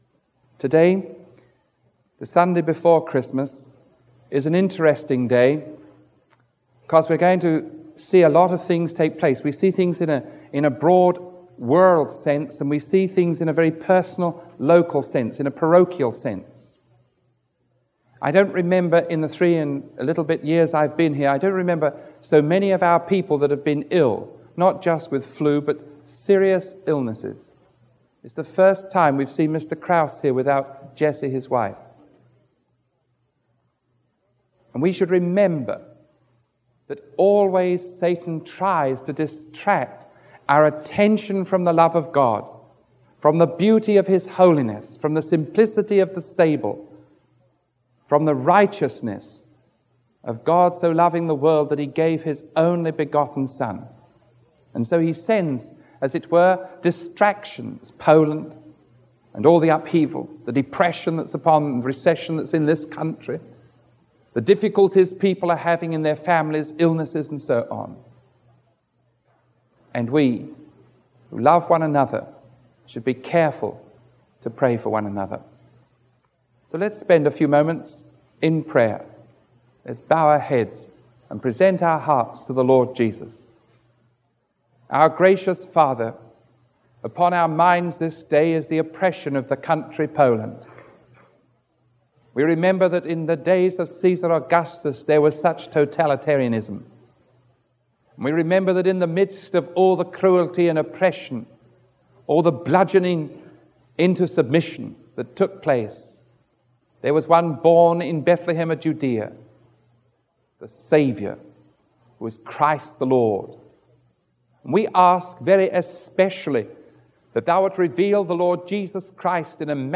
Sermon 0464A recorded on December 20